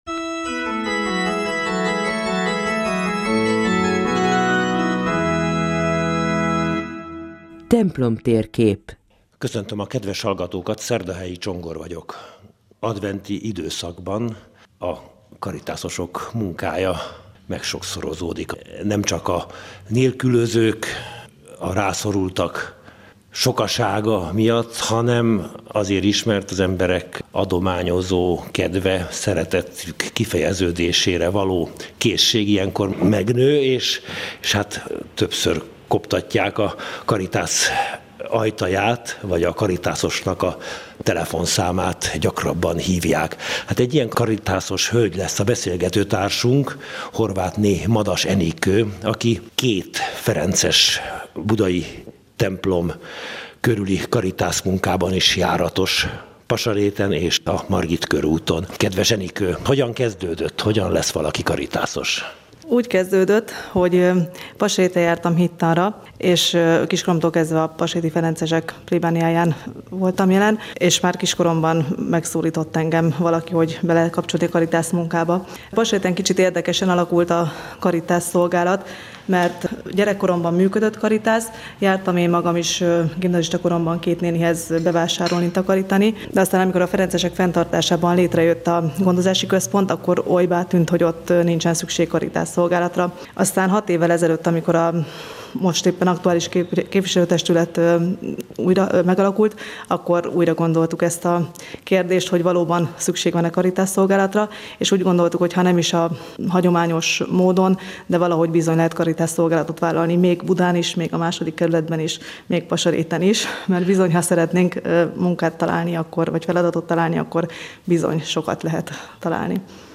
a Katolikus Rádióban